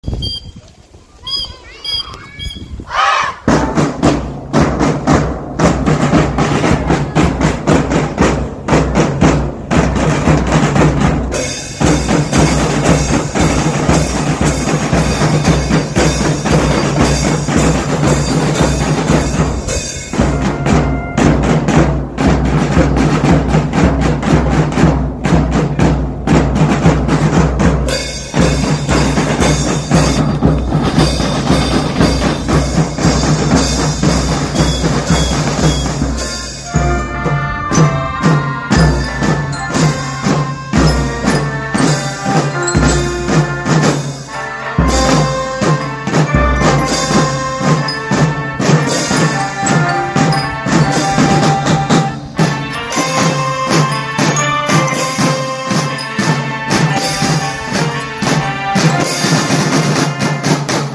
音でます♪鼓笛隊 6年生
鼓笛隊0622-online-audio-converter.com_.mp3